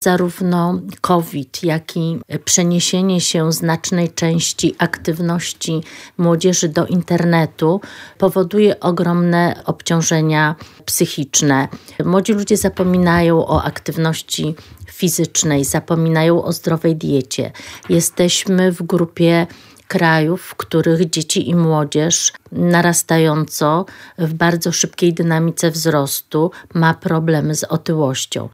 Trwają prace nad podstawą programową – mówiła w Radiu Lublin posłanka Platformy Obywatelskiej, Bożena Lisowska.